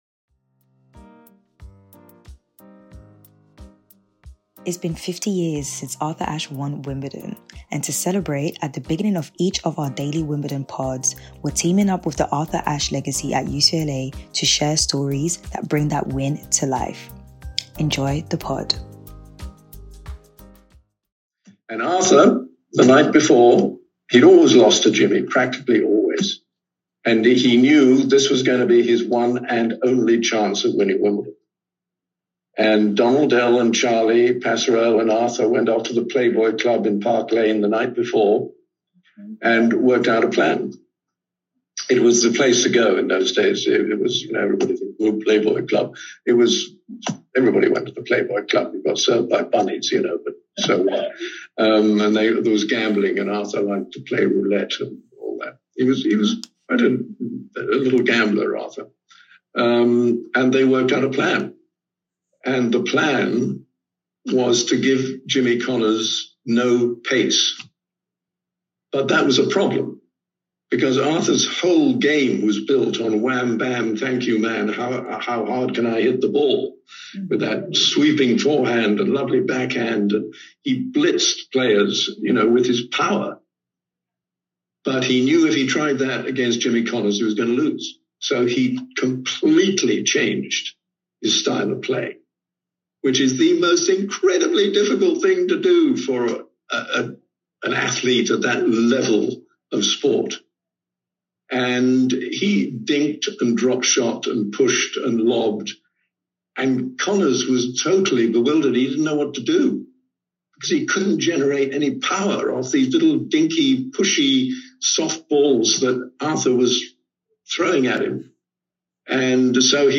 We discuss and share post-match press snippets from Tiafoe, Osaka and Diallo.